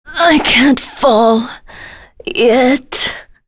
Tanya-Vox_Dead.wav